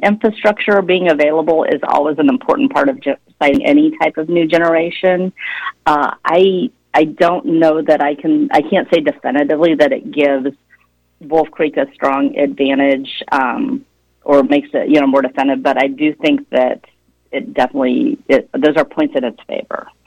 She expanded on that answer during an interview on KVOE’s Morning Show this week.